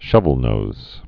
(shŭvəl-nōz)